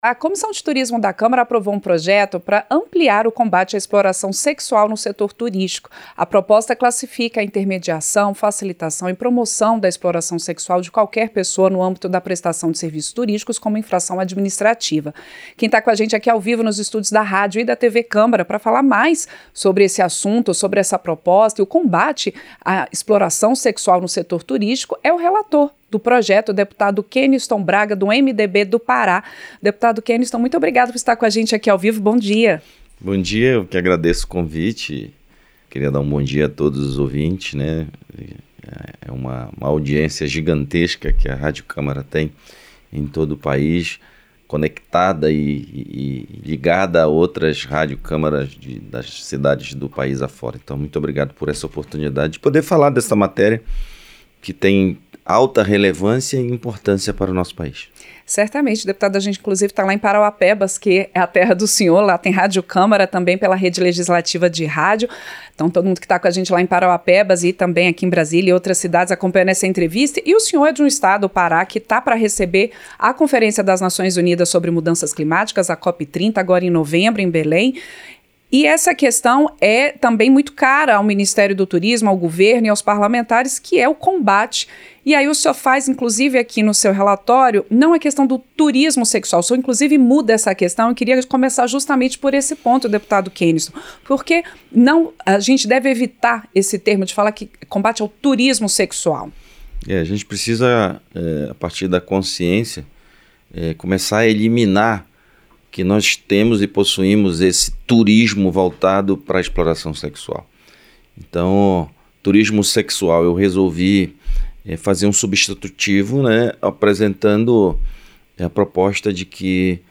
• Entrevista - Dep. Keniston Braga (MDB-PA)
Programa ao vivo com reportagens, entrevistas sobre temas relacionados à Câmara dos Deputados, e o que vai ser destaque durante a semana.